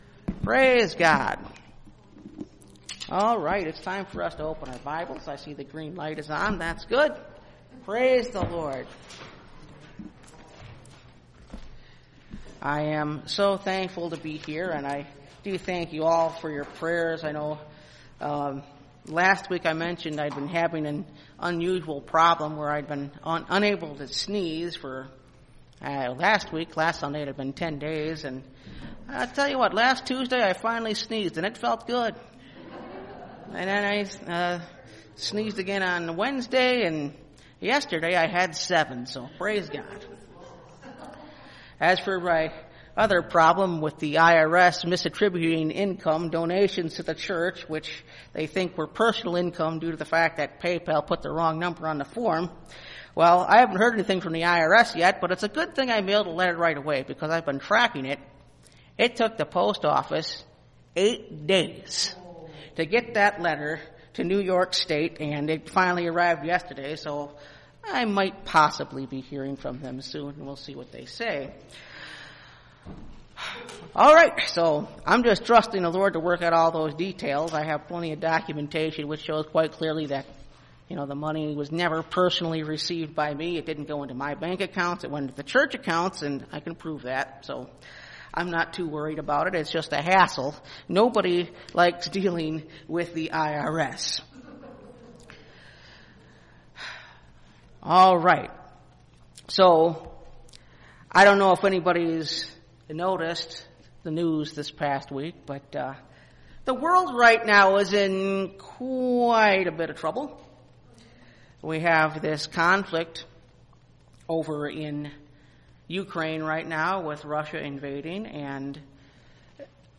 The Essentials Part 4 (Message Audio) – Last Trumpet Ministries – Truth Tabernacle – Sermon Library